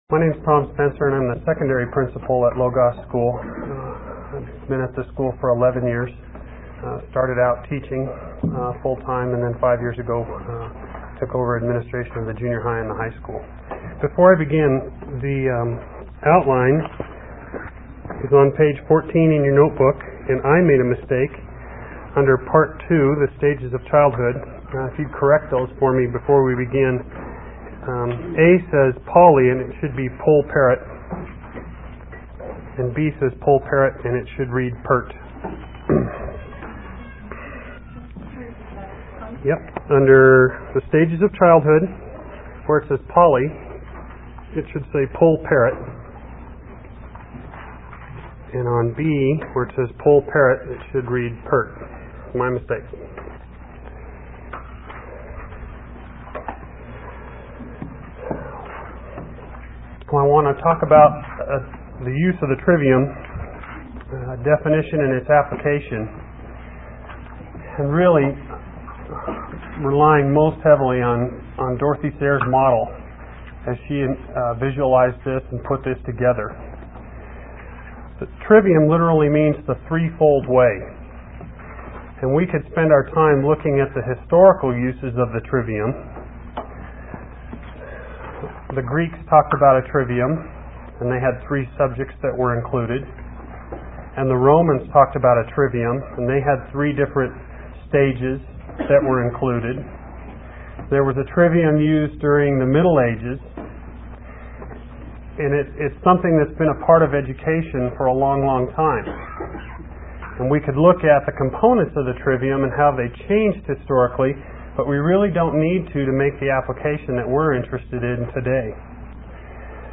1995 Workshop Talk | 0:39:08 | All Grade Levels